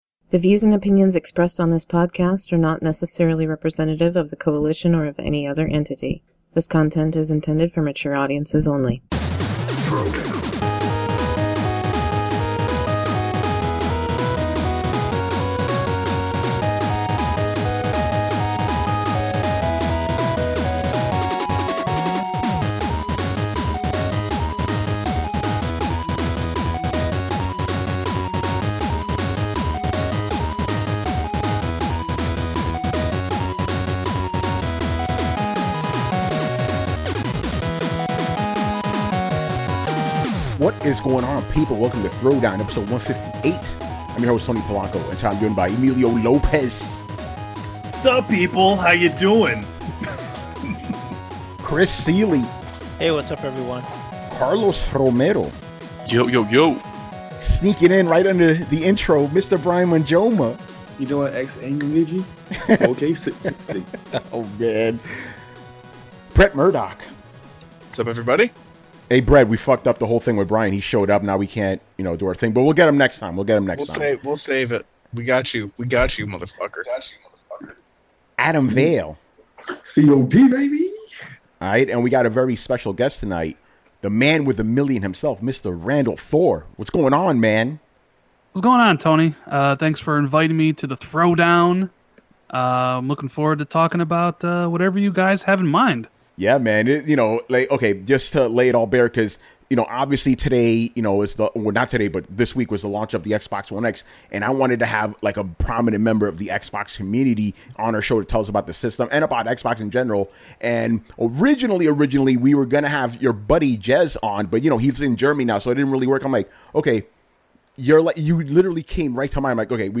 We discuss the release of the Xbox One X with special guest